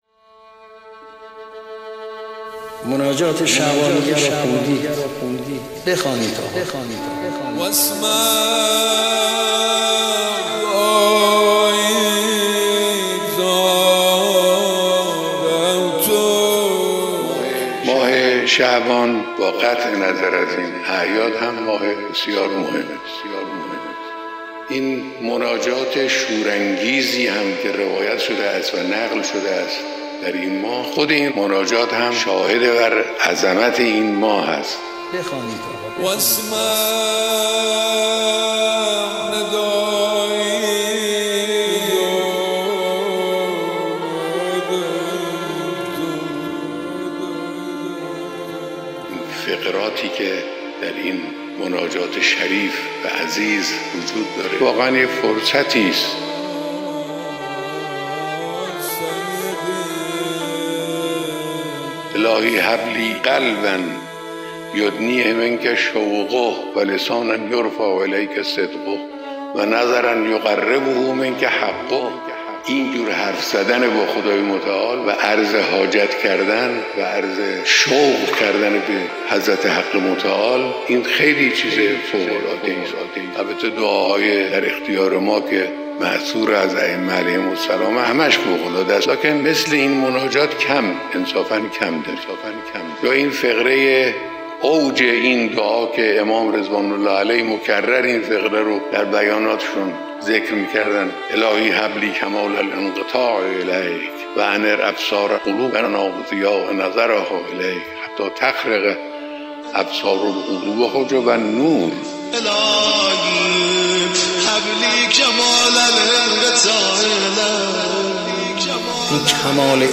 گزیده بیانات رهبر انقلاب درباره مناجات شعبانیه؛